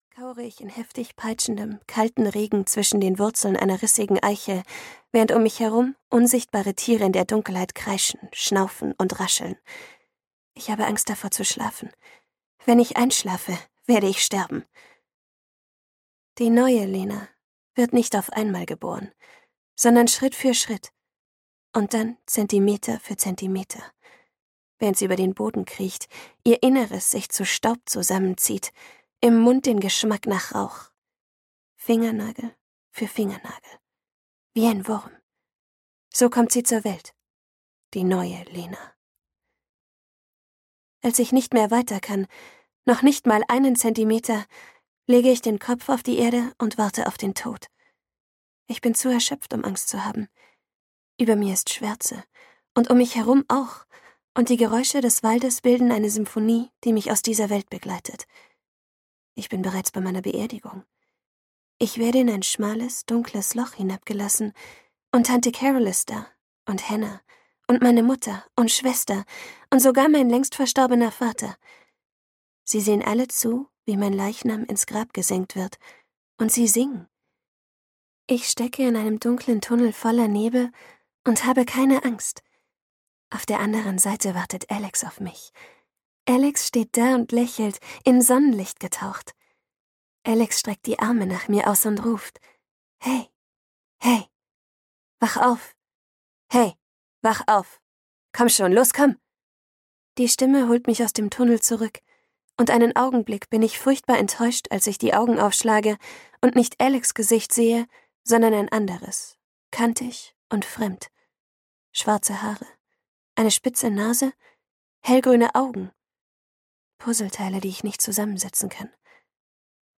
Amor-Trilogie 2: Pandemonium - Lauren Oliver - Hörbuch